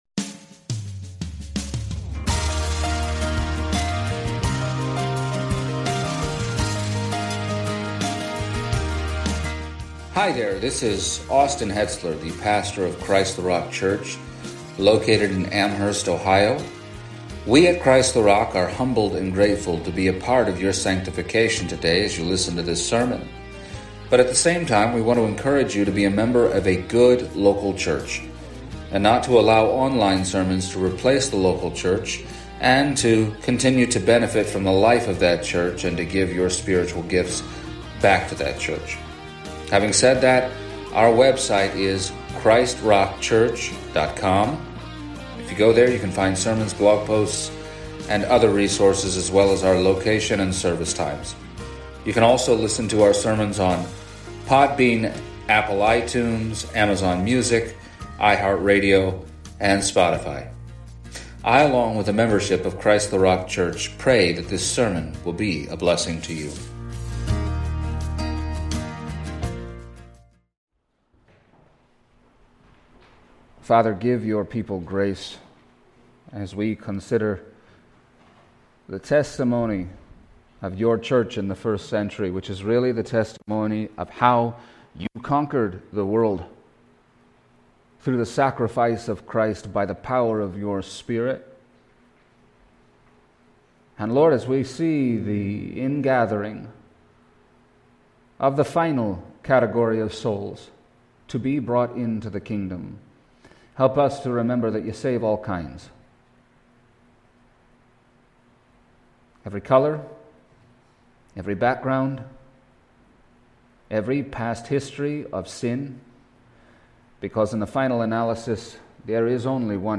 Passage: Acts 19:1-7 Service Type: Sunday Morning